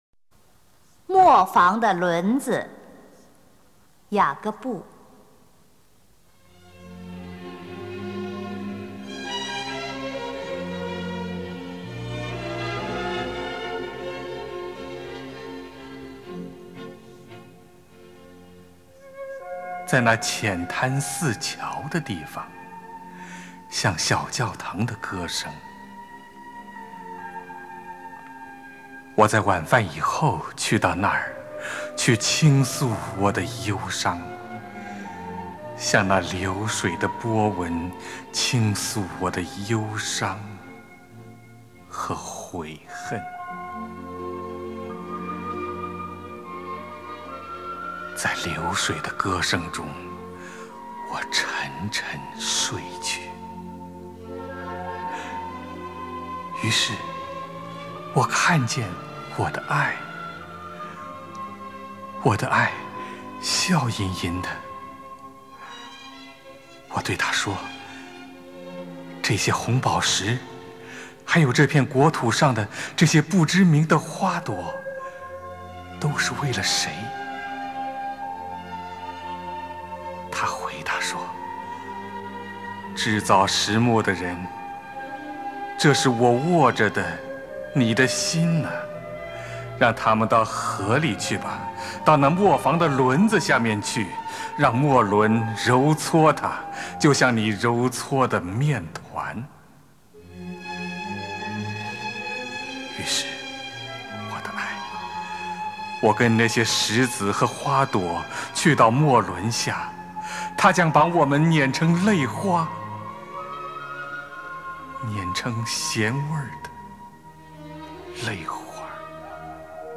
[1/6/2010]磨房的轮子 乔榛 外国著名爱情诗选 配乐诗朗诵